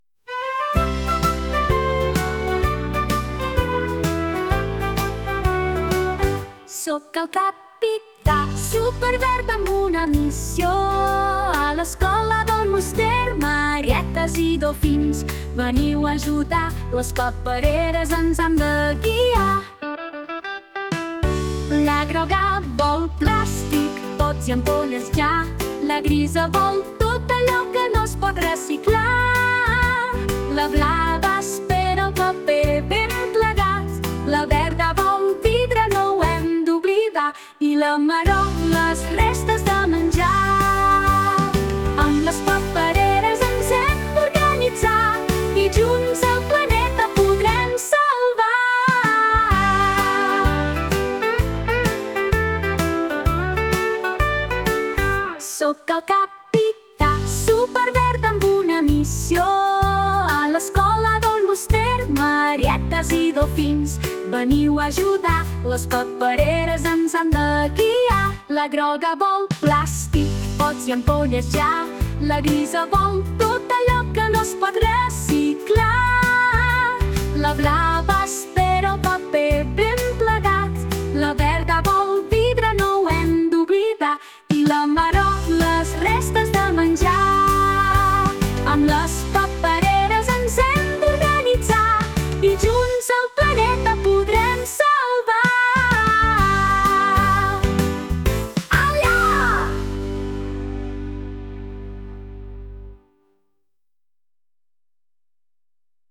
Us deixem la cançó que ens ha portat de regal per poder-la ballar a casa!